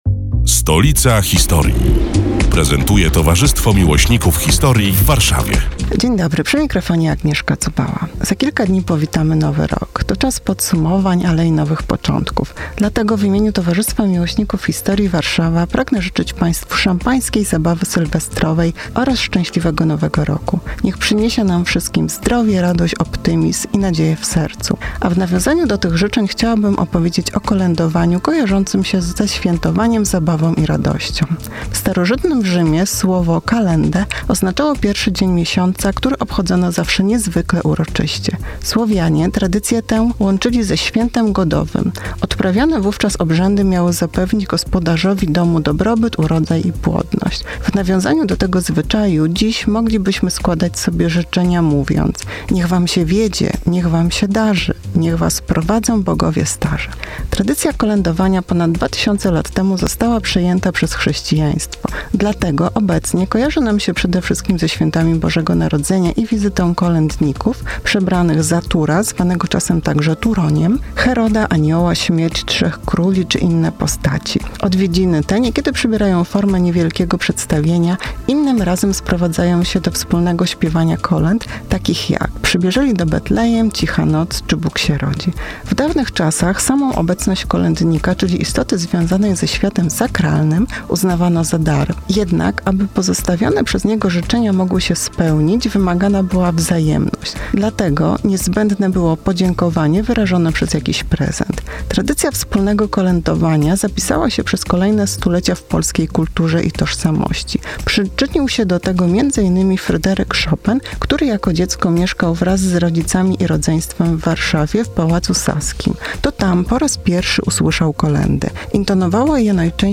Felieton